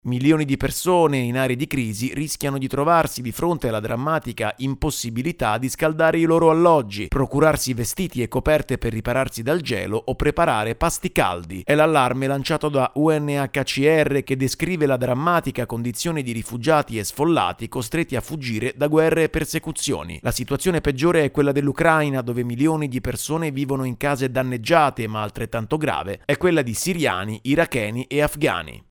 Haiti vive da mesi in un clima di violenza e insicurezza alimentare. Il servizio